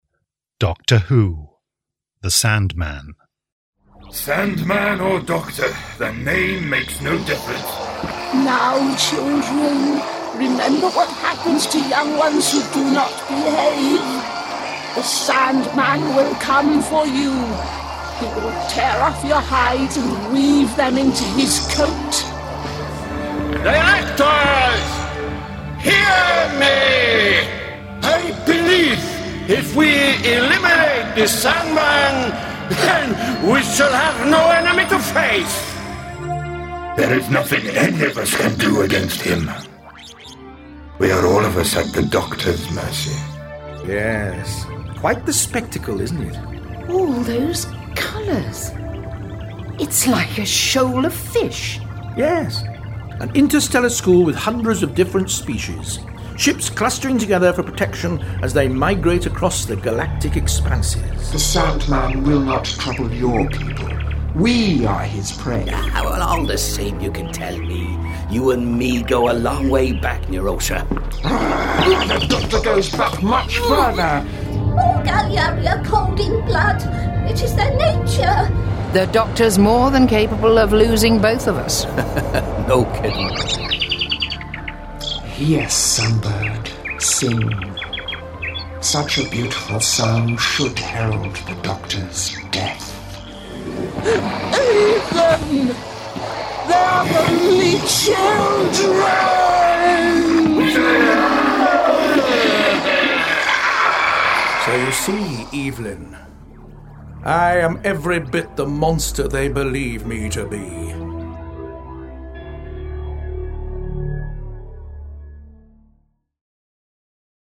Award-winning, full-cast original audio dramas from the worlds of Doctor Who